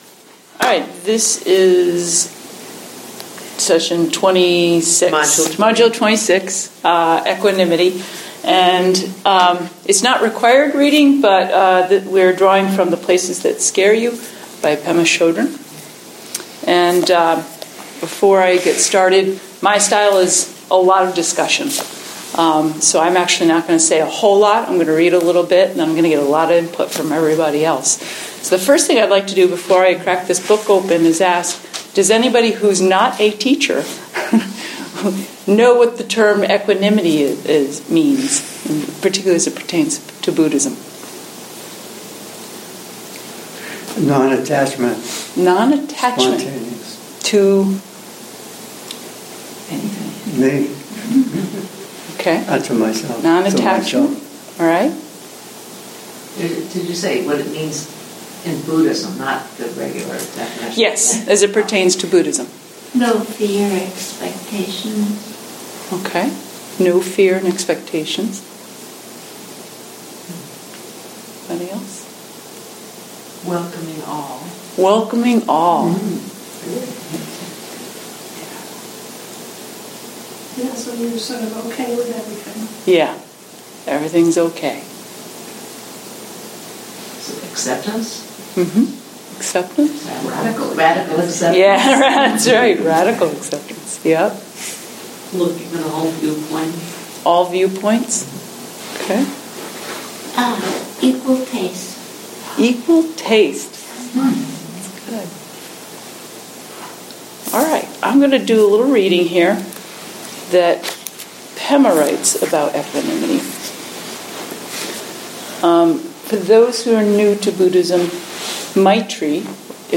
Audio of discussion .